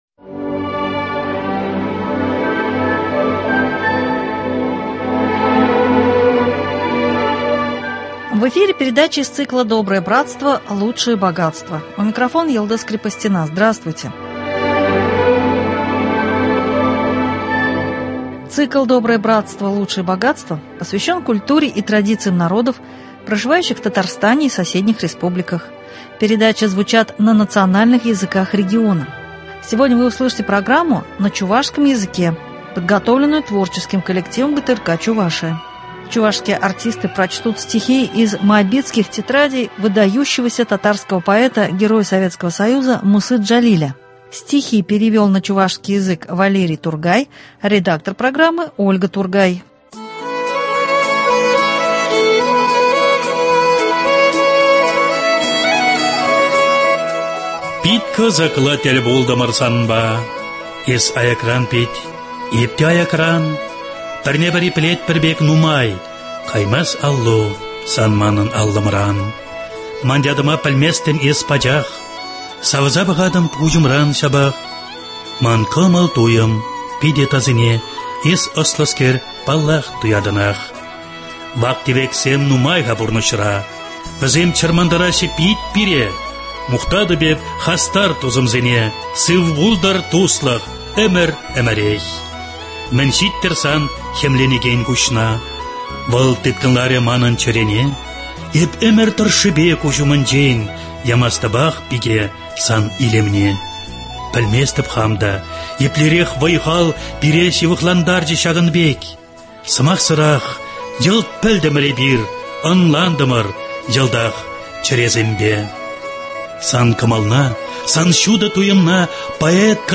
Чувашские артисты прочтут стихи из Моабитских тетрадей выдающегося татарского поэта, героя Советского Союза Мусы Джалиля.